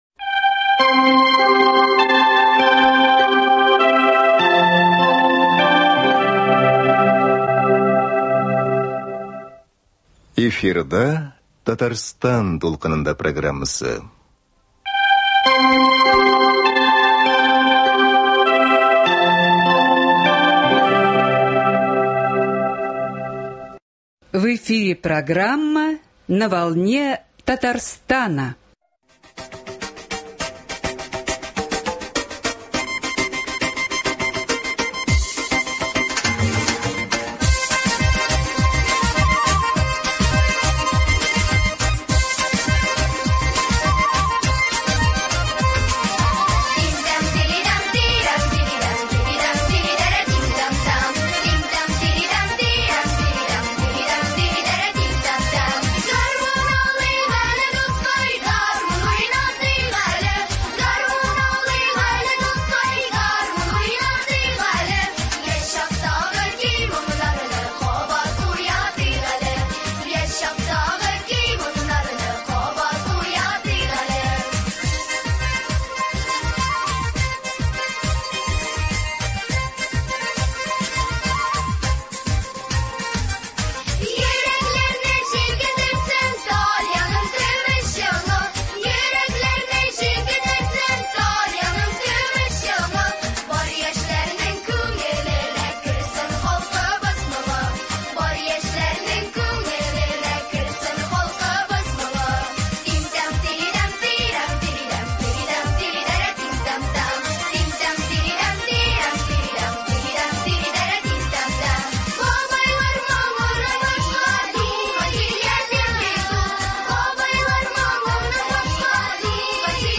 Студия кунагы — Татарстанның атказанган сәнгать эшлеклесе, композитор